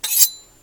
sword.3.ogg